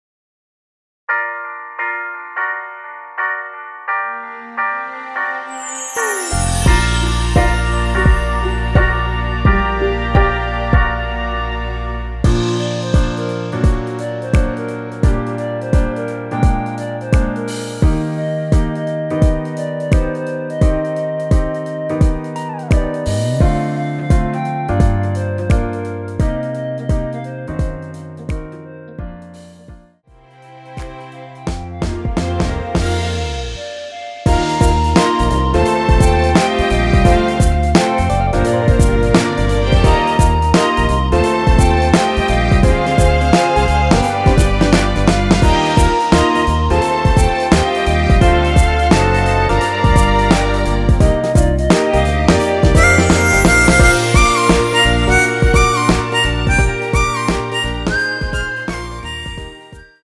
원키에서(+2)올린 멜로디 포함된 MR입니다.
Bb
앞부분30초, 뒷부분30초씩 편집해서 올려 드리고 있습니다.
중간에 음이 끈어지고 다시 나오는 이유는